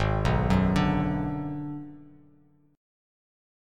G#m7b5 chord